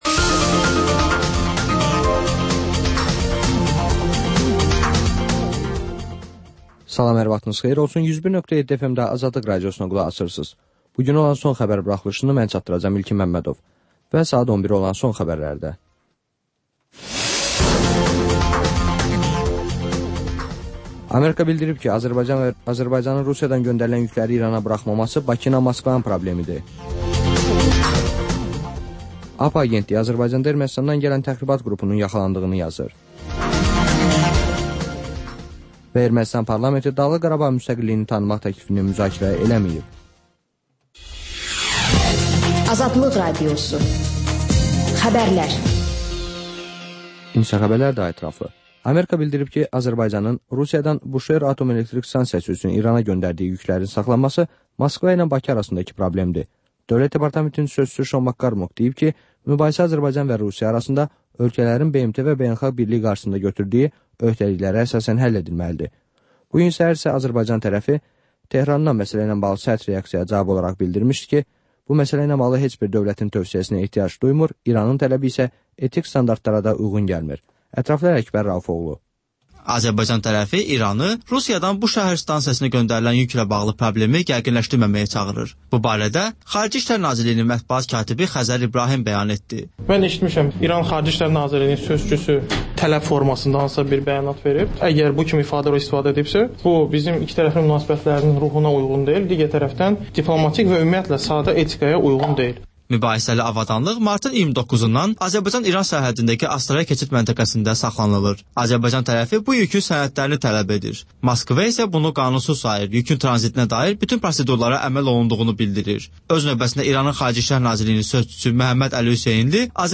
Xəbərlər, müsahibələr, hadisələrin müzakirəsi, təhlillər, sonda isə XÜSUSİ REPORTAJ rubrikası: Ölkənin ictimai-siyasi həyatına dair müxbir araşdırmaları